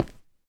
sounds / step / stone2.ogg
stone2.ogg